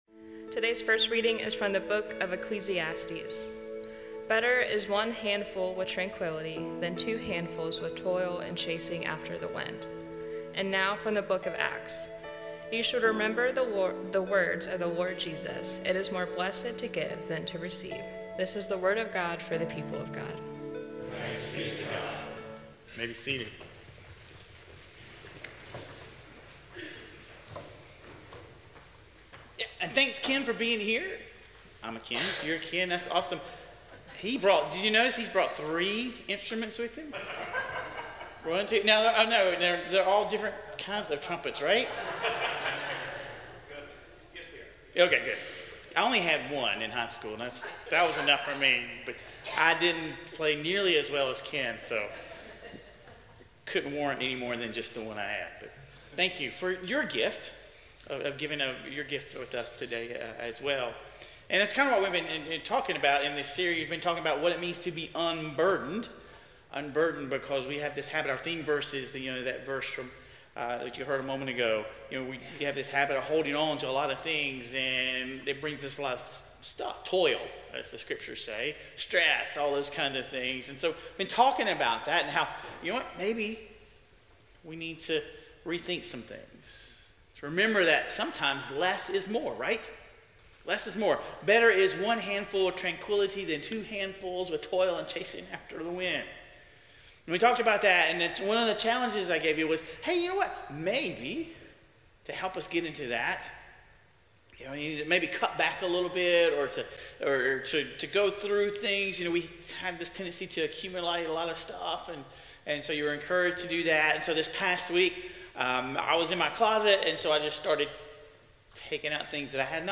The sermon addresses the "scarcity mindset" (the fear of not having enough) as a barrier to generosity.